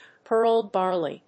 アクセントpéarl bárley